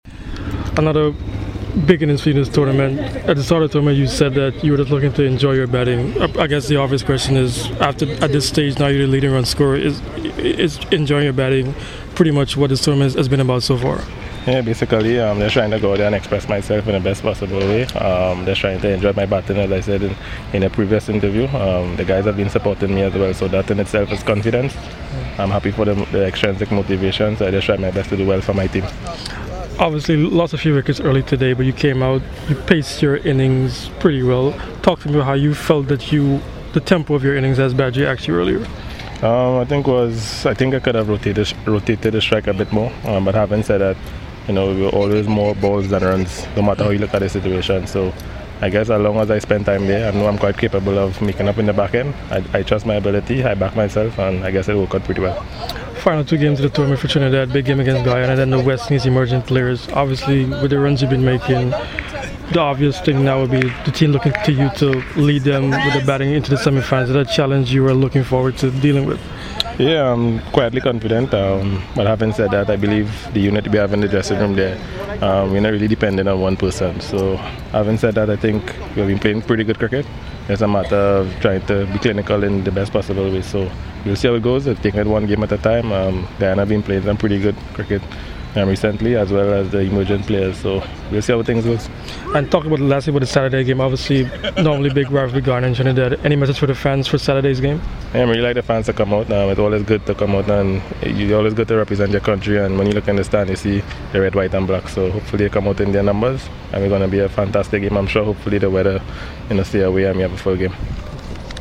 Darren Bravo spoke to CWI Media after Zone “B” # Super50